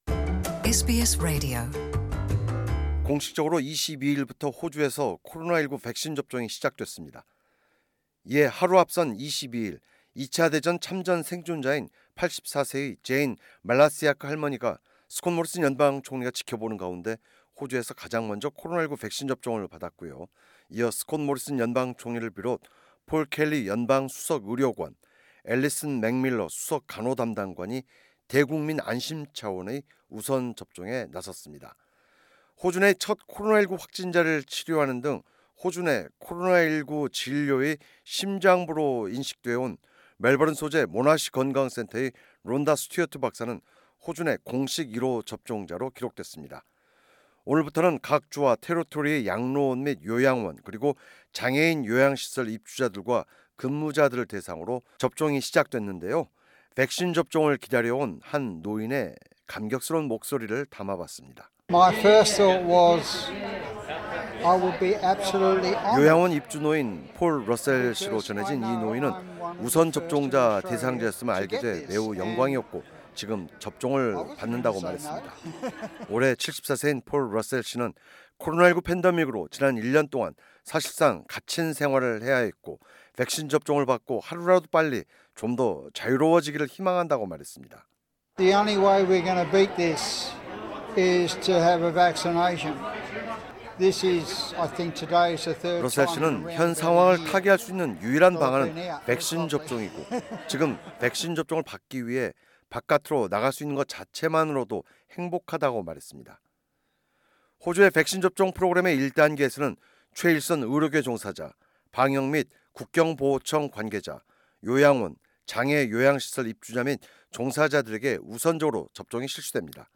백신 접종을 기다려온 한 노인의 감격해 하는 목소리 담아봤습니다.